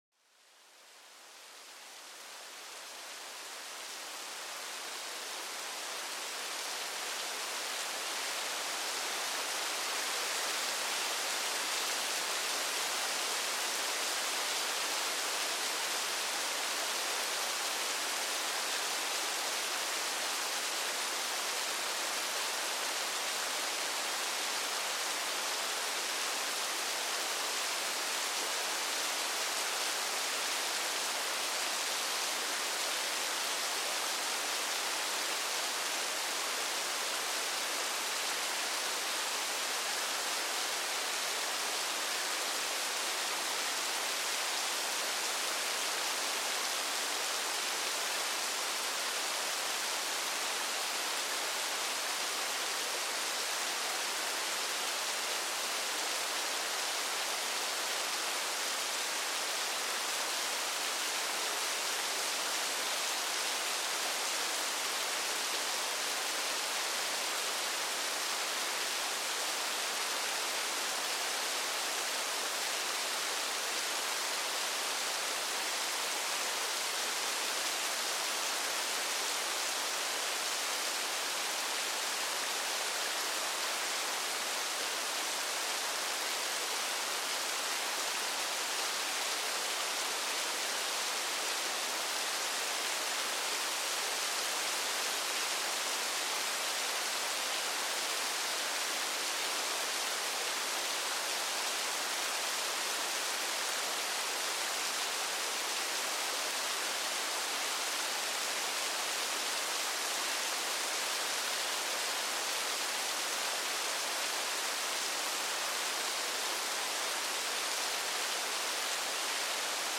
Le murmure apaisant de la pluie en forêt pour calmer l'esprit